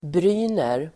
Uttal: [br'y:ner]